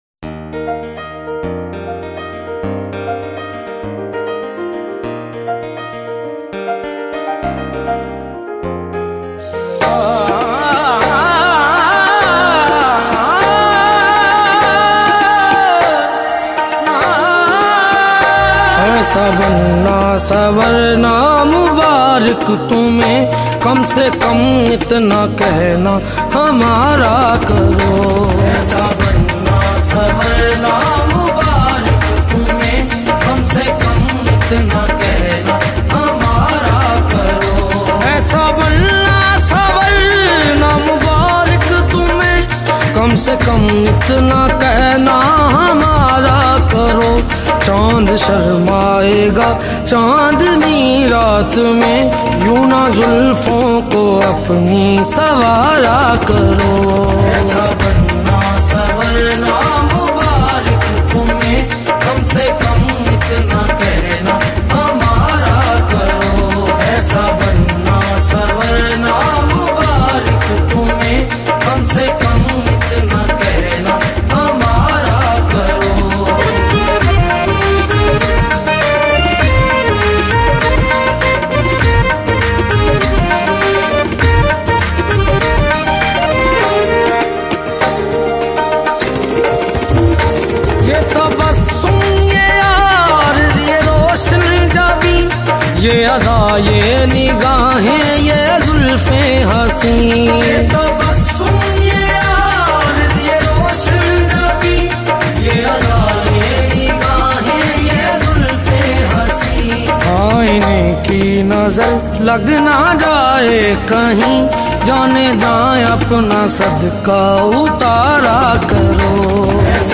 qawwali style devotional song